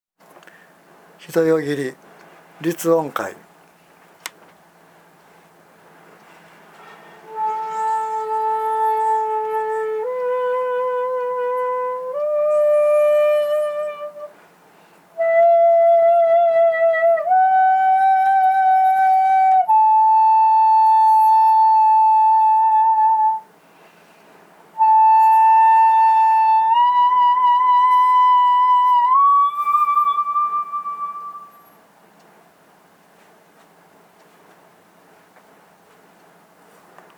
形は少々違っていますが、短簫も一節切も出る音が同じなのです。即ち使われている音階が一致するのです。
まず一節切の音階表を見ながら音を聴いてください。
（一節切の音階音源）